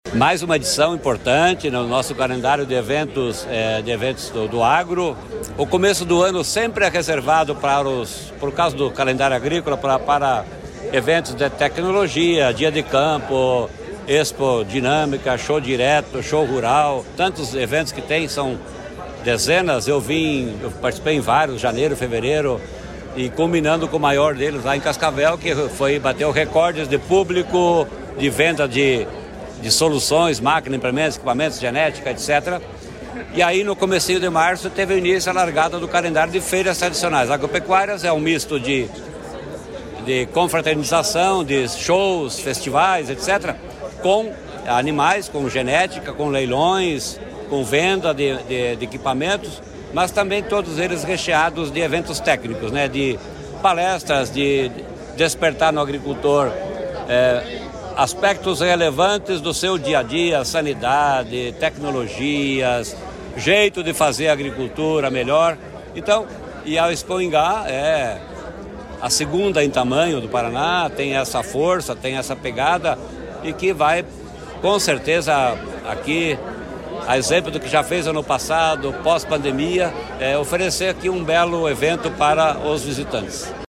Sonora do secretário Estadual da Agricultura e do Abastecimento, Norberto Ortigara, na abertura da 49ª Expoingá